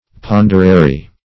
Search Result for " ponderary" : The Collaborative International Dictionary of English v.0.48: Ponderary \Pon"der*a*ry\, a. Of or pertaining to weight; as, a ponderary system.